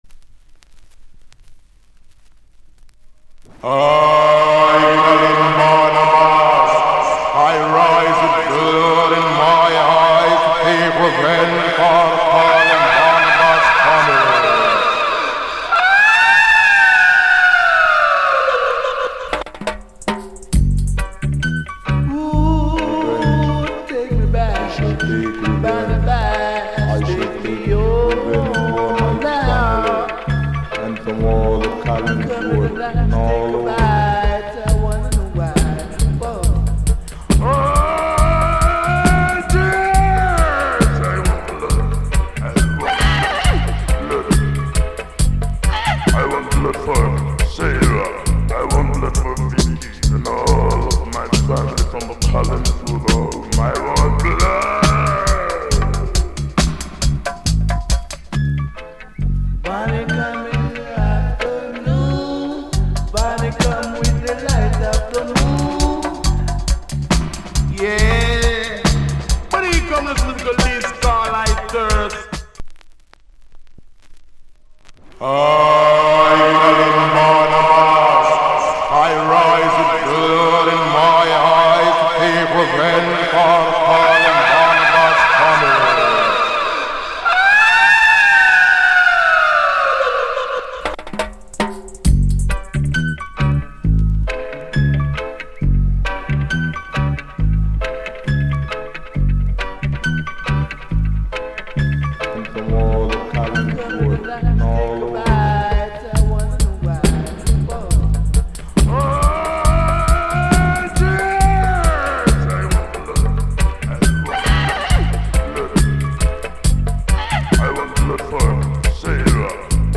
Production Genre Reggae70sLate / Male DJ